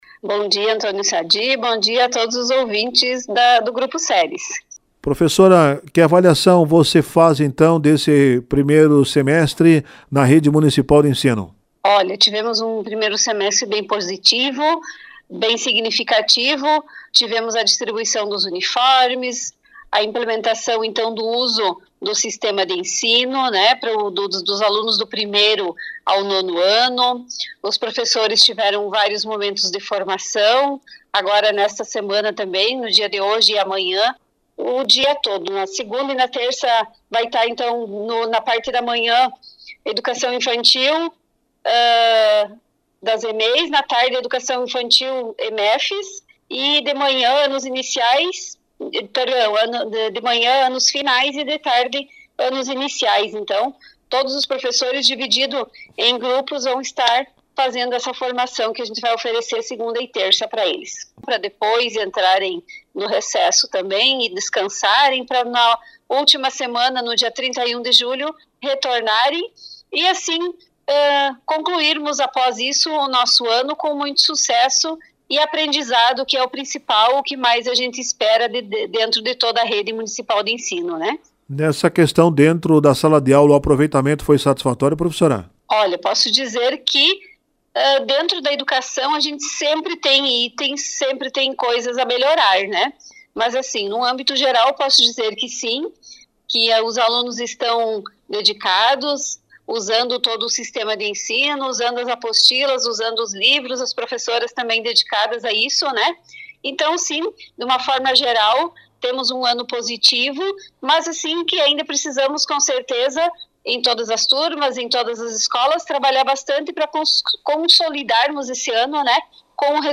Em entrevista para o Grupo Ceres de Comunicação, a secretária de educação, Luciana Renz, destacou que as EMEIS (Escolas Municipais de Educação Infantil) seguem atendendo os alunos da educação infantil normalmente durante o recesso escolar.
Ouça a entrevista: 1707_CN_LUCIANA HENKES RENZ EDUCAÇÃO